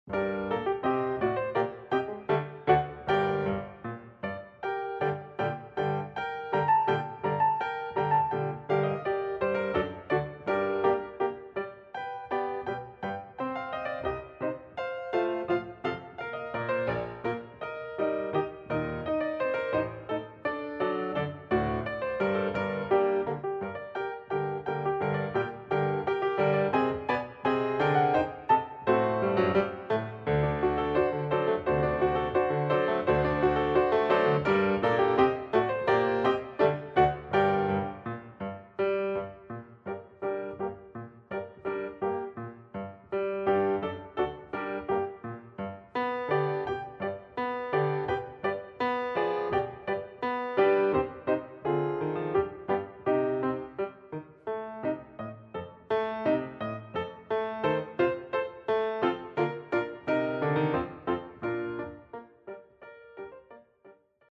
Voicing: String Bass and Piano